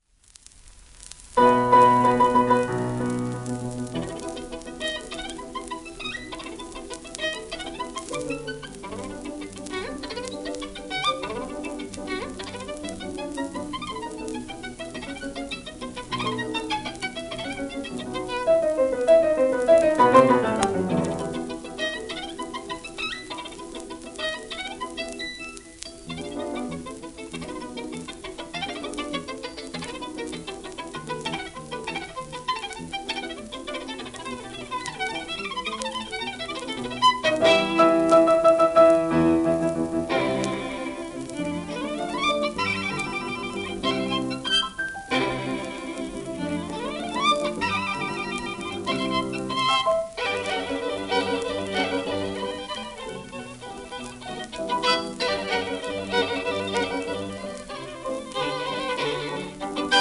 1937年録音